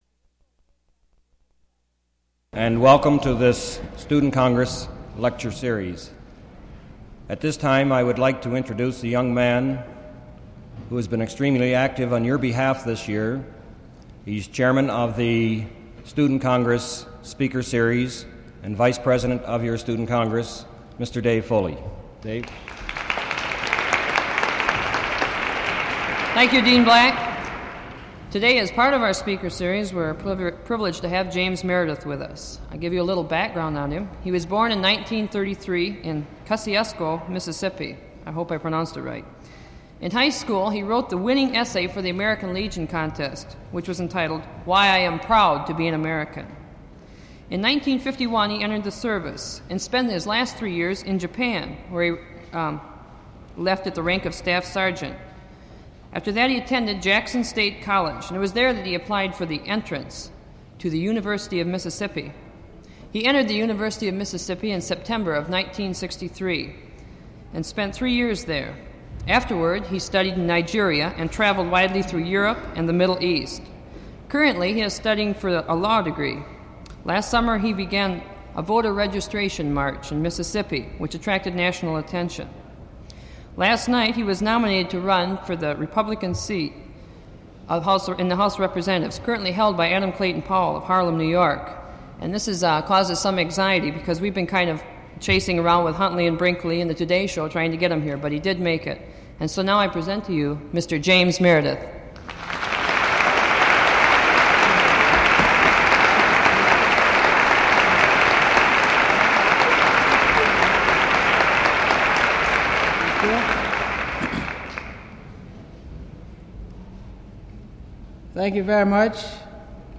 Today, I want to post an audio file of a lecture that civil rights activist James Meredith gave in March of 1967 at Fountain Street Church.